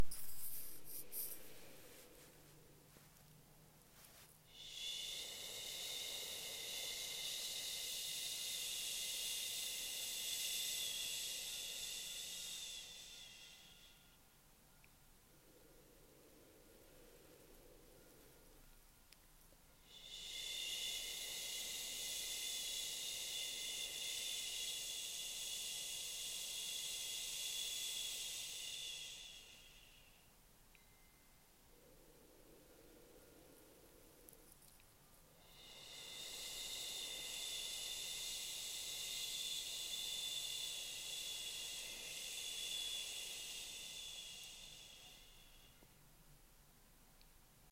First Sound: SHU
Similarity: like making the sound “shhhh”